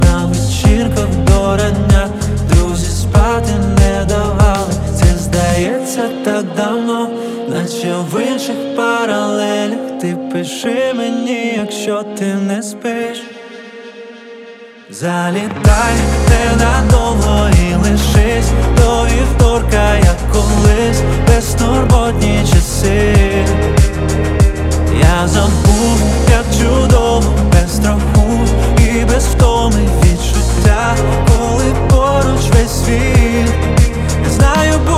Жанр: Поп музыка / Русский поп / Русские
Pop